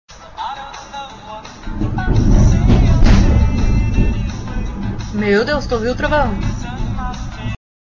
Play, download and share Trovão original sound button!!!!
trovao.mp3